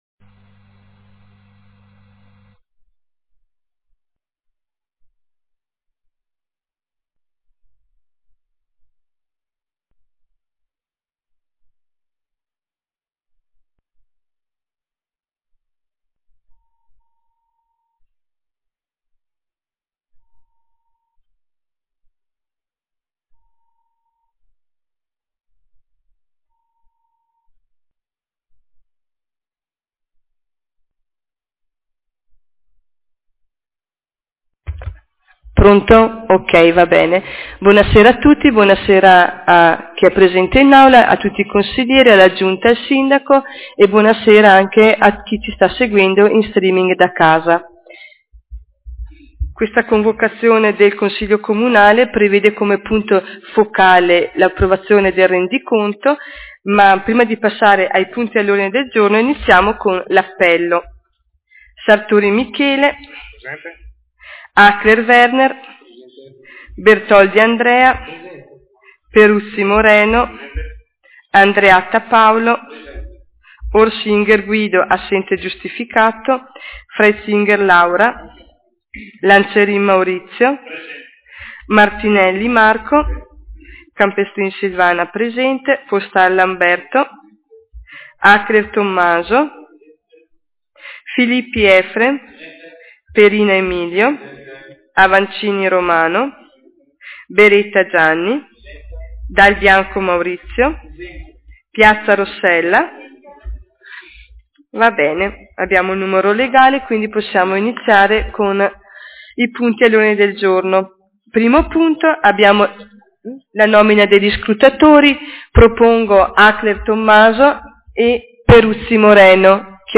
Audio della seduta